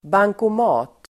Uttal: [bangkom'a:t]